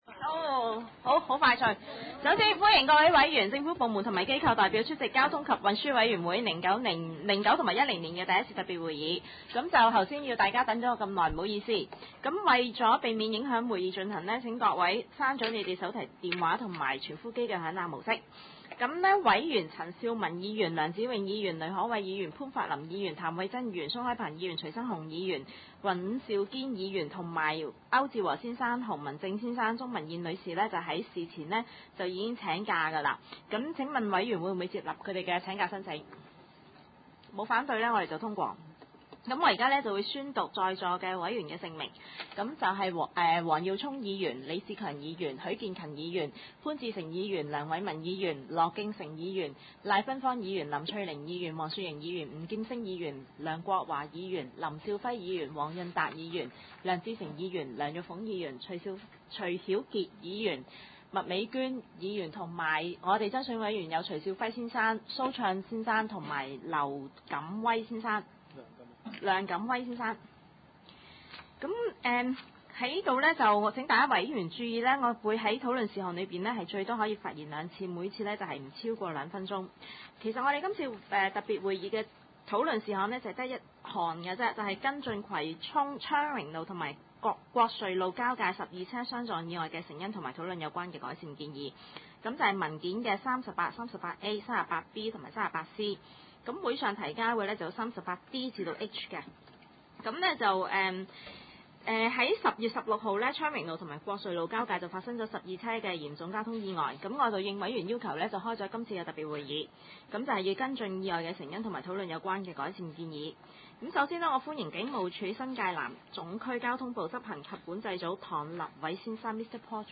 葵 青區議會 交通及運輸委員會 第一次特別會議 (零九/一零) 日期 : 2009年10月 27 日（星期 二 ） 時間 : 下午二時三十分 地點 : 香港葵涌興芳路 166-174 號 葵興政府合署 10 樓 葵青民政事務處會議室 議 程 討論時間 討論事項 1.